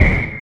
Energy Hit 03.wav